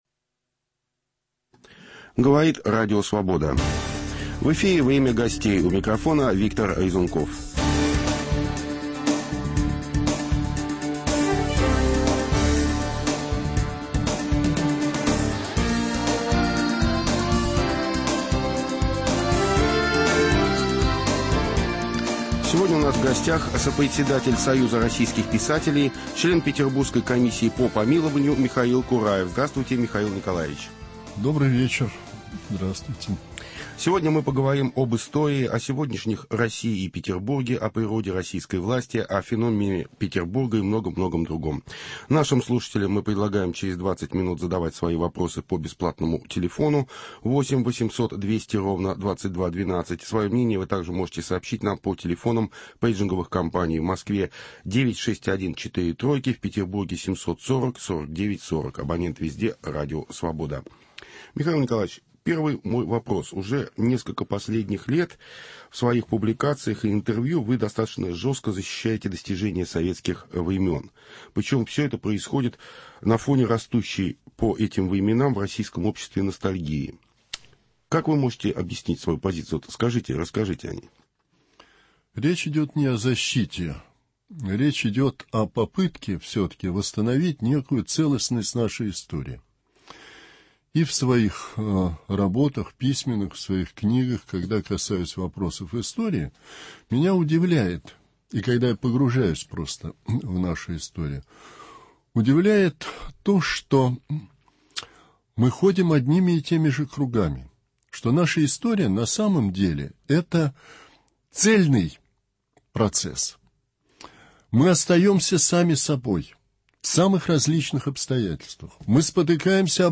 Сегодняшние Россия и Петербург глазами писателя. В гостях - сопредседатель Союза российских писателей,член петербургской комиссии по помилованию Михаил Кураев.